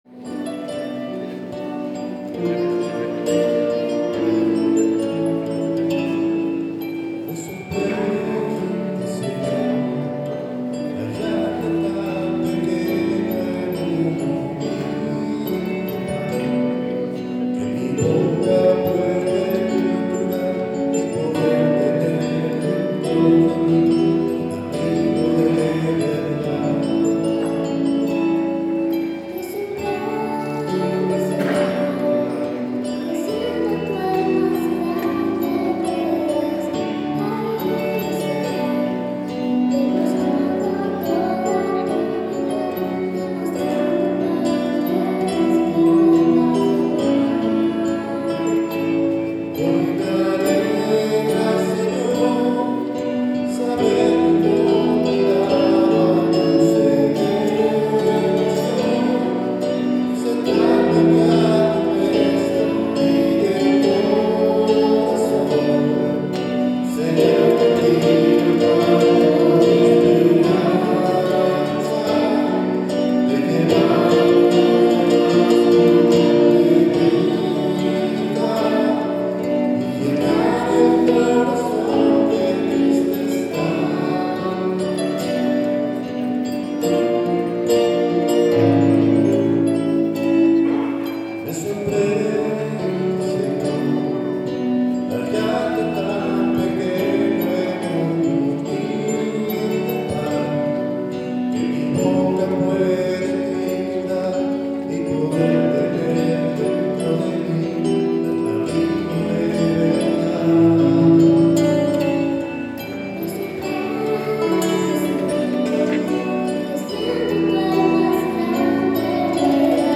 Tempo 65
Ritmo: Pop